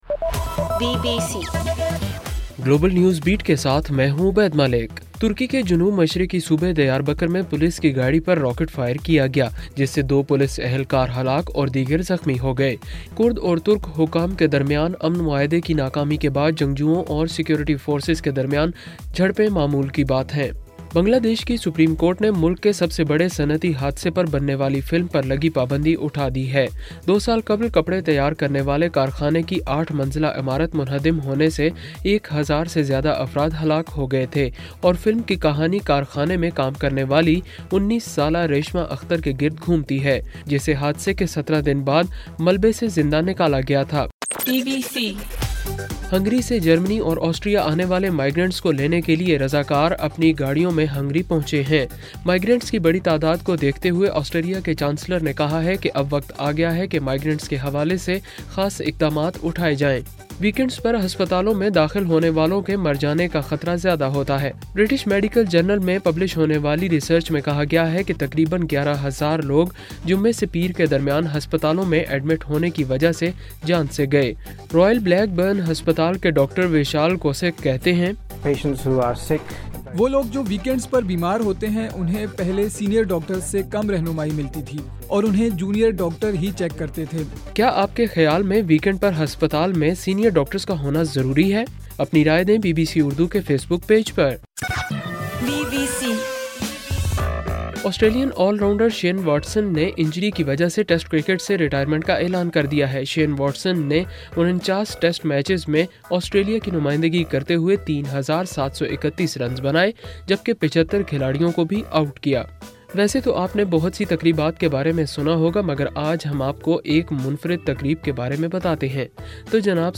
ستمبر 6: رات 12 بجے کا گلوبل نیوز بیٹ بُلیٹن